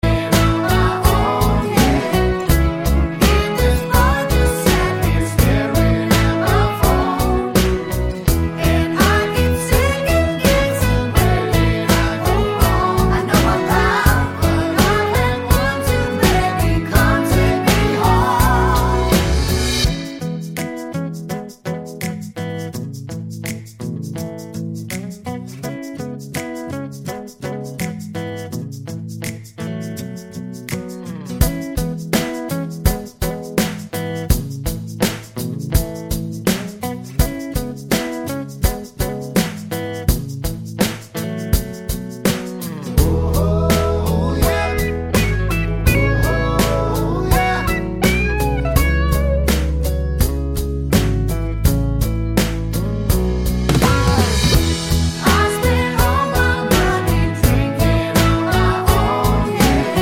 no Backing Vocals Duets 3:24 Buy £1.50